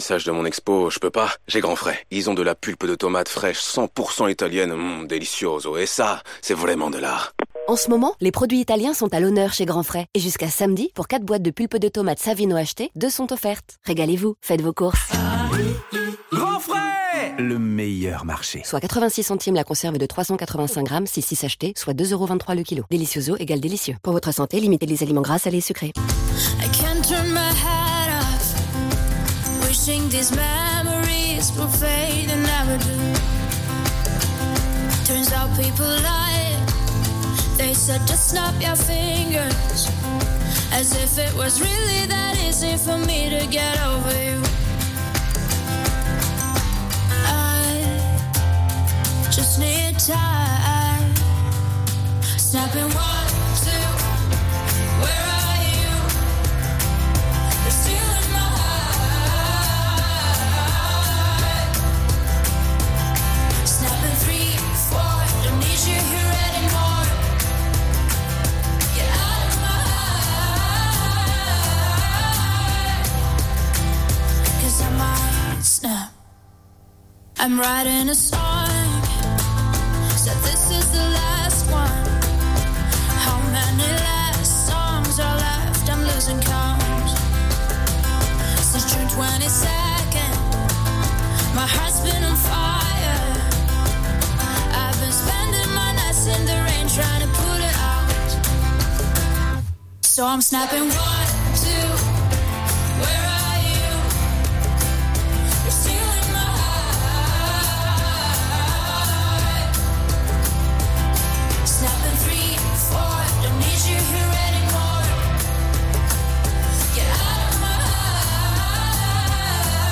Journal du mercredi 13 septembre (midi)